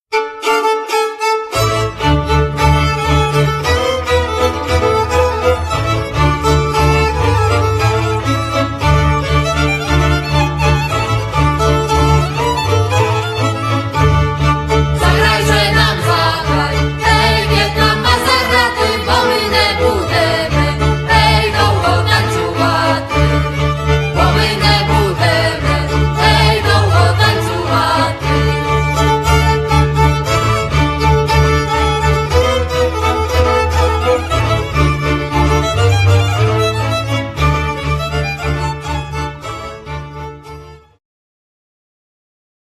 skrzypce, altówki, ¶piew solo violin, violas, solo vocals
kontrabas doublebass